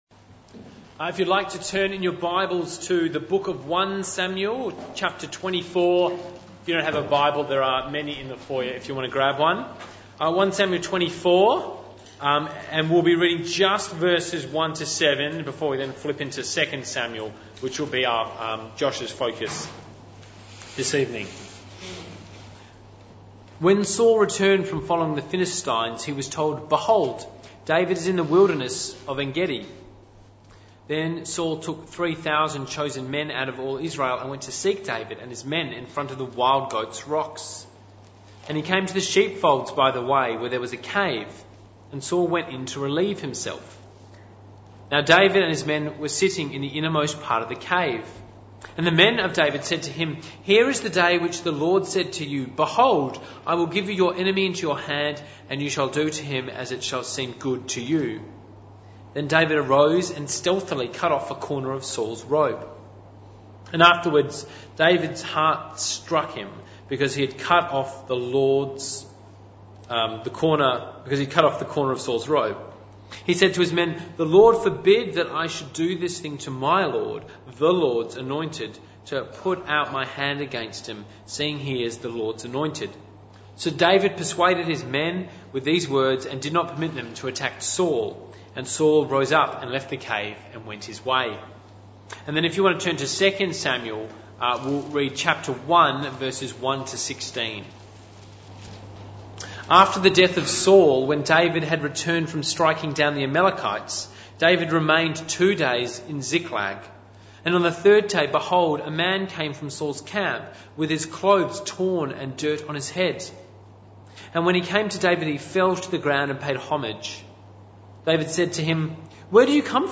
This talk was part of the PM Service series entitled The Rise of the Fallen Kings (Talk 10 of 15).